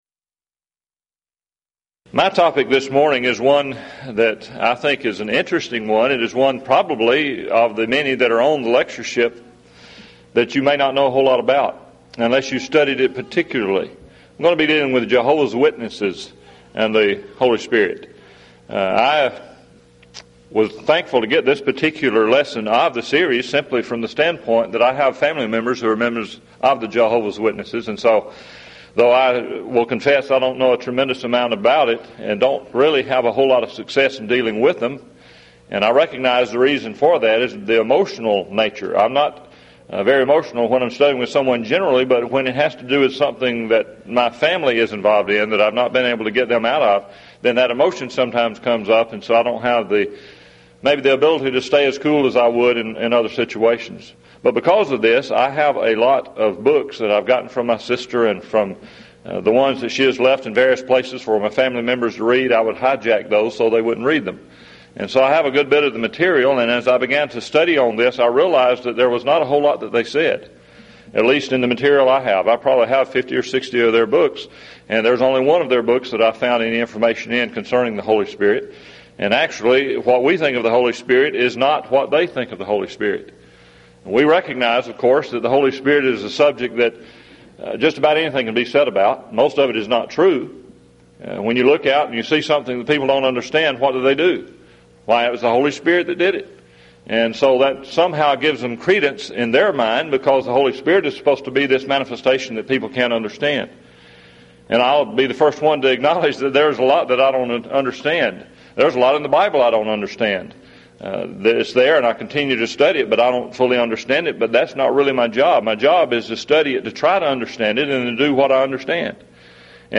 Event: 1997 Mid-West Lectures
lecture